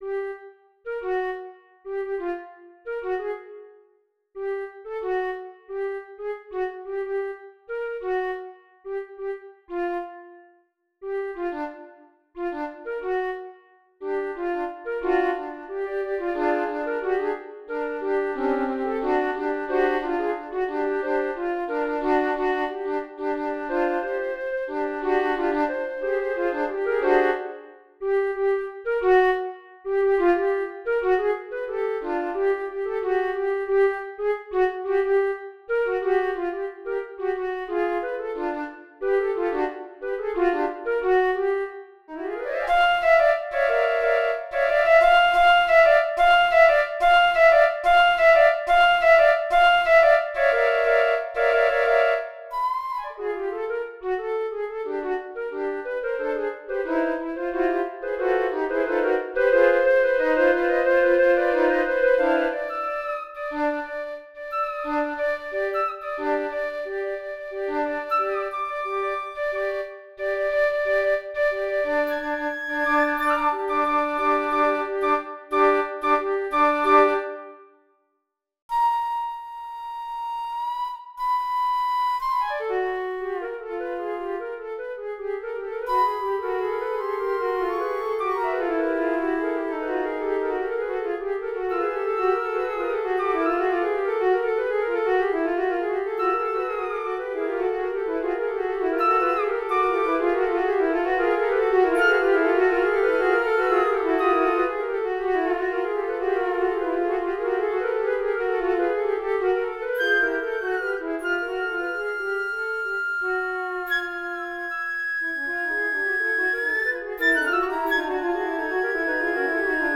ABSTRACT: In this research-creation postgraduate project, I sought to create a work that hybridizes avant-garde composition techniques such as micropolyphony, extended techniques, controlled spontaneity, microtonalism, loops, spectral sonorities, randomness and sonorities reminiscent of electroacoustics, with characteristic elements of some traditional Colombian music, such as the cumbia de gaitas, the alabao, the tambora of Altos del Rosario and the bambuco.
2.2 Icterus Icterus - Audio Midi.wav